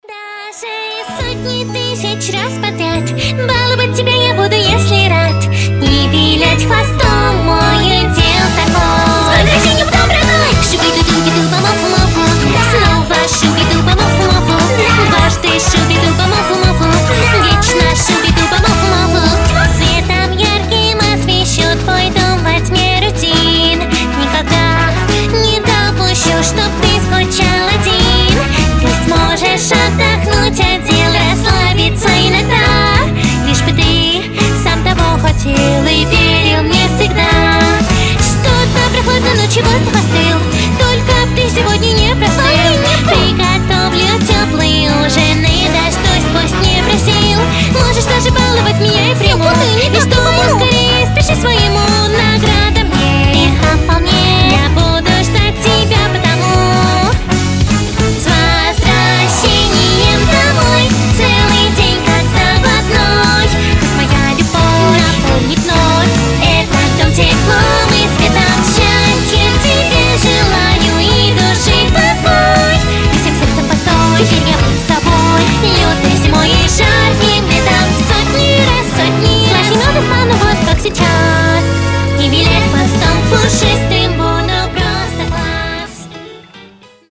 • Качество: 320, Stereo
милые
добрые
аниме